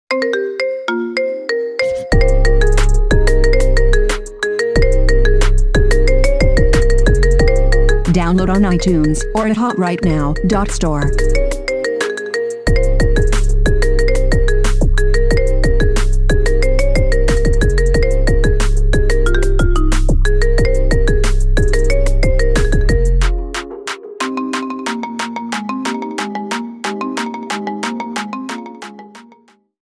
Kategorien Marimba Remix